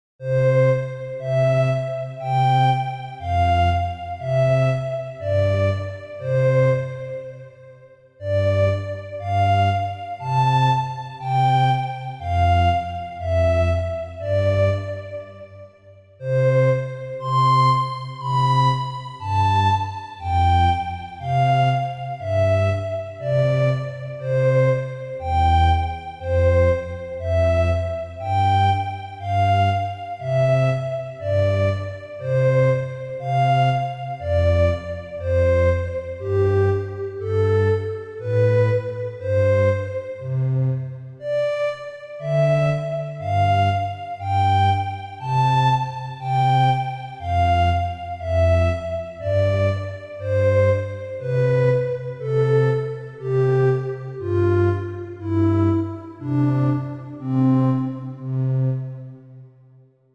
オルガン風。のどかな村や教会などのイメージです。
BPM60
ゆっくり
荘厳